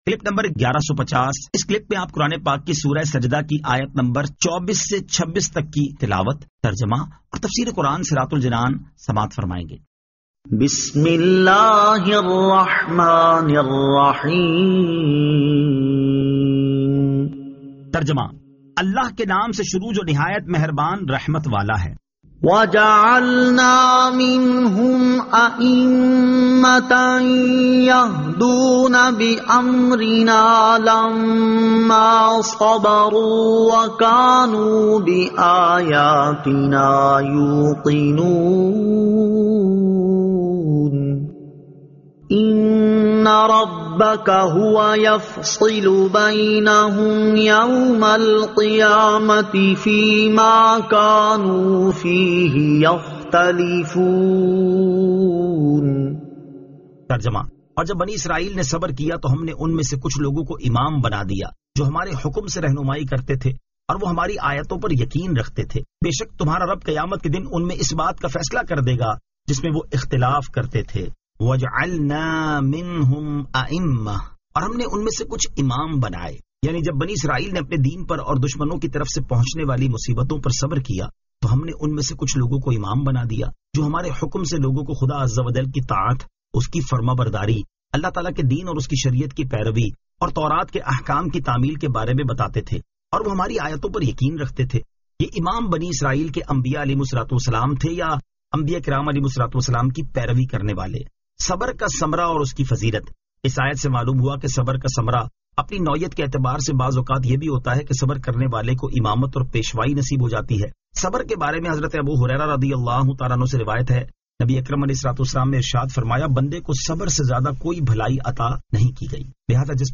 Surah As-Sajda 24 To 26 Tilawat , Tarjama , Tafseer